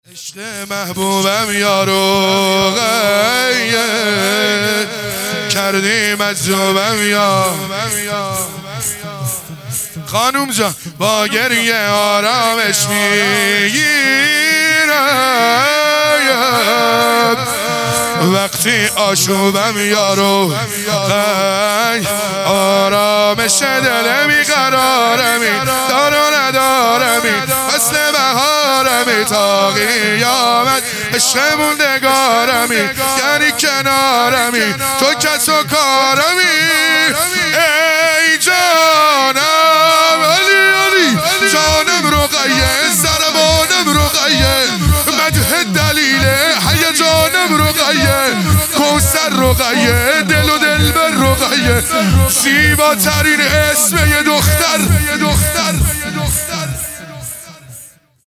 شهادت حضرت زینب (س) | 27 بهمن ماه 1400 | شور | عشق محبوبم یا رقیه
شهادت حضرت زینب (س)